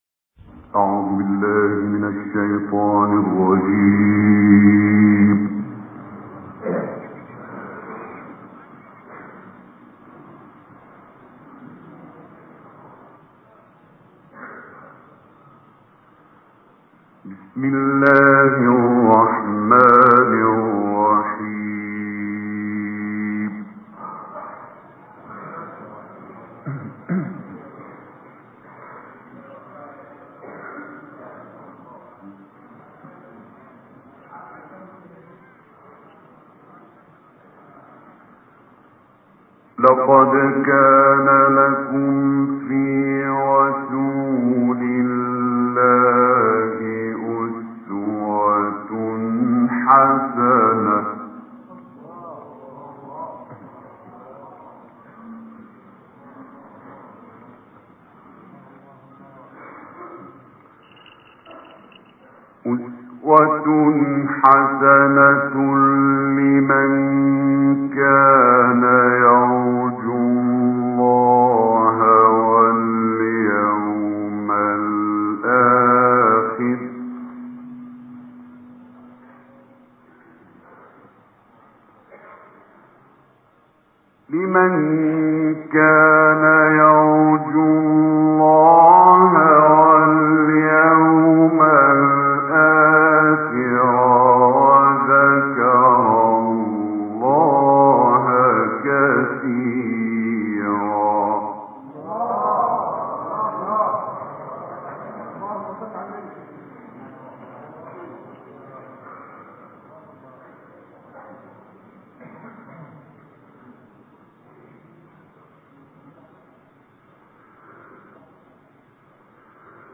ملف صوتی ما تيسر من سورة الاحزاب - 1 بصوت إبراهيم عبدالفتاح الشعشاعي